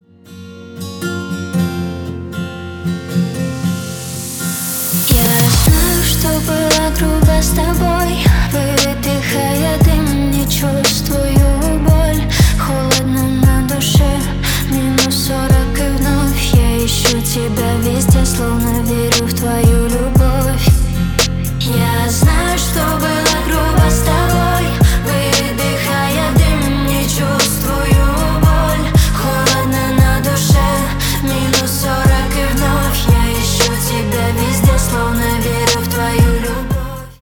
• Качество: 320 kbps, Stereo
Поп Музыка
грустные
спокойные